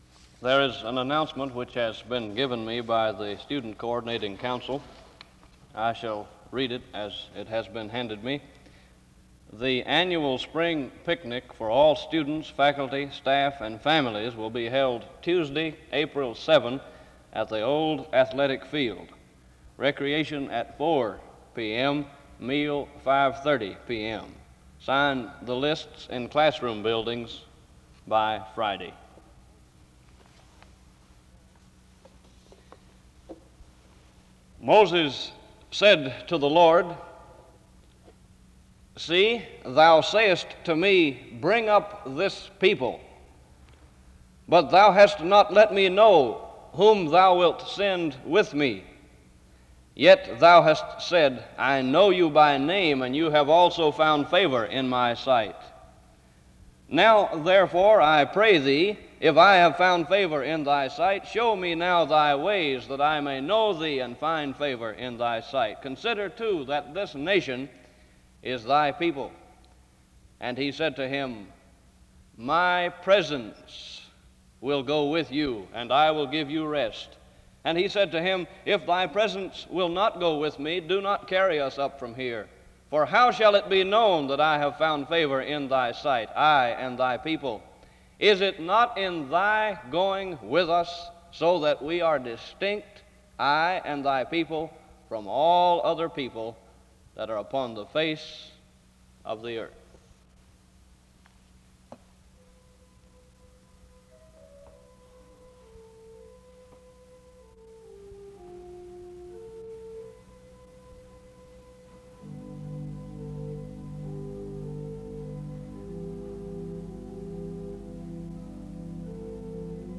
The service begins with an announcement and scripture reading from 0:00-1:42. Music plays from 1:44-3:57. A prayer is offered from 4:04-5:47.
SEBTS Chapel and Special Event Recordings SEBTS Chapel and Special Event Recordings